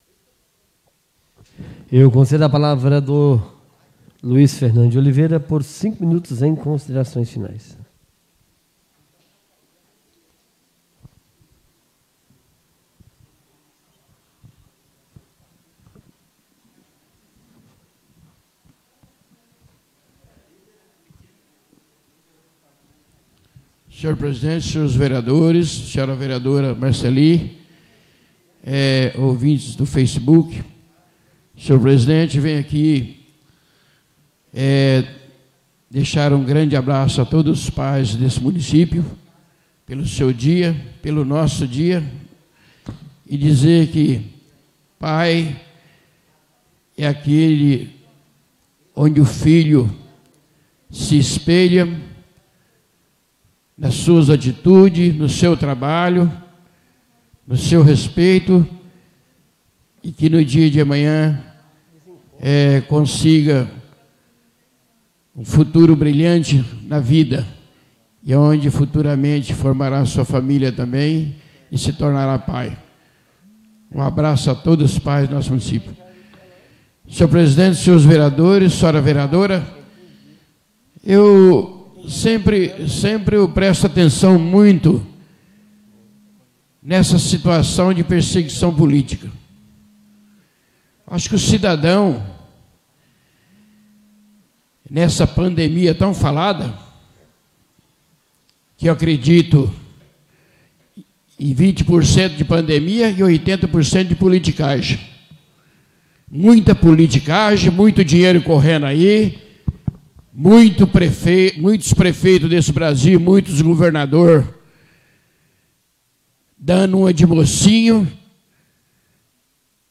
Oradores das Explicações Pessoais (22ª Ordinária da 4ª Sessão Legislativa da 6ª Legislatura)